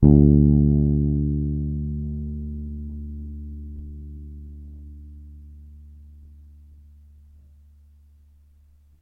描述：无板贝司演奏D2音符。
Zoom H2。贝斯直接通过外部麦克风输入。
Tag: 低音 手指 无品 多重采样